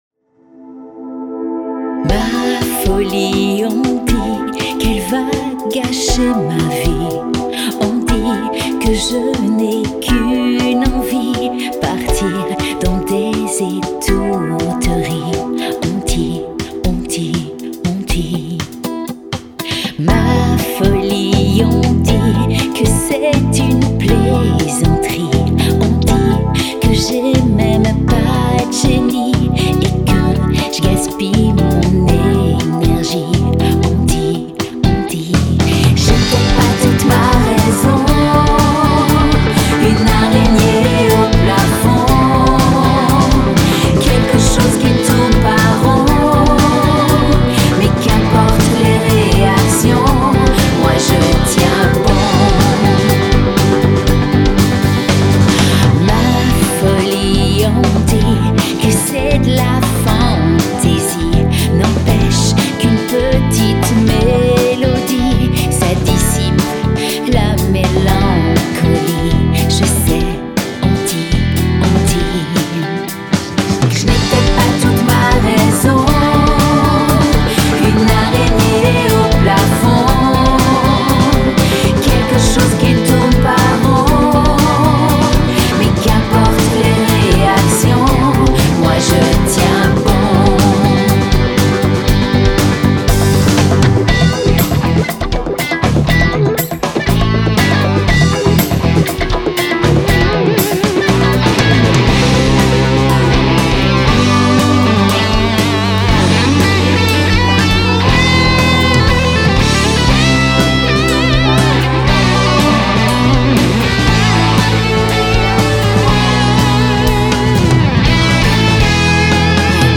arrangements et claviers
arrangements et batterie
guitares
voix féminine